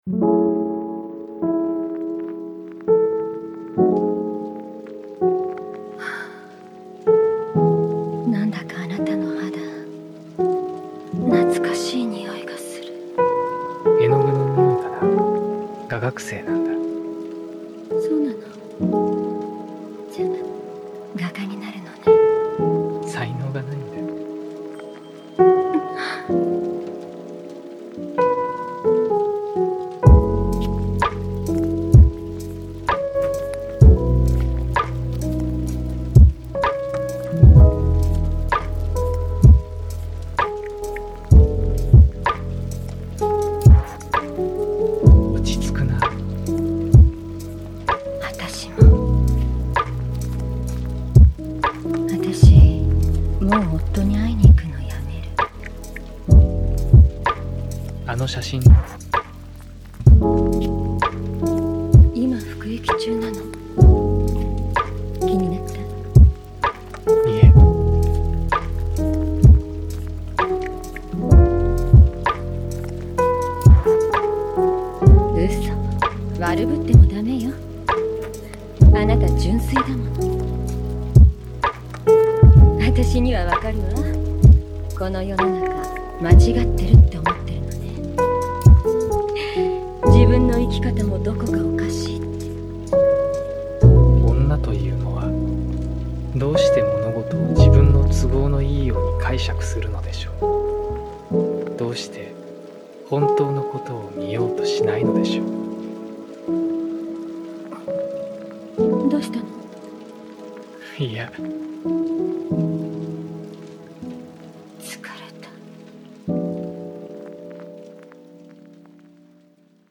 Pomodoro Pluie : Concentration